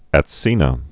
(ăt-sēnə)